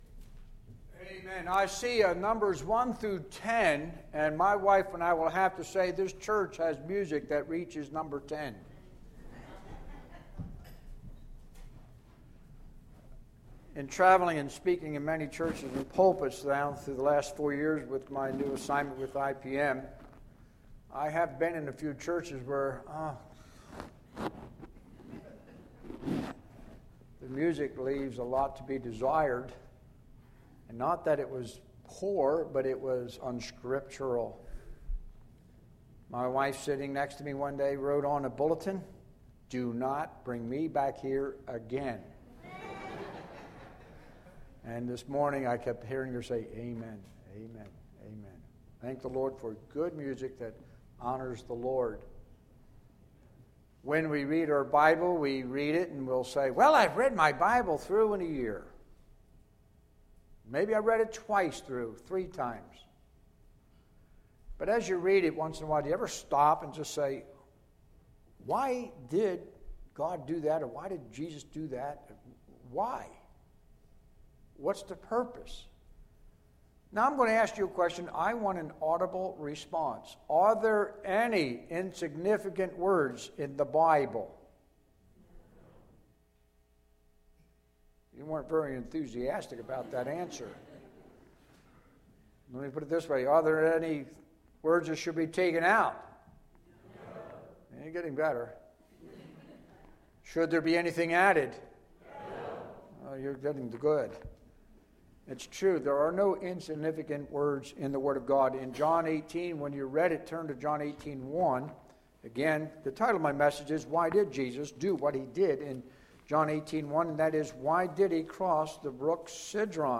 Sunday, August 14, 2011 – Morning Message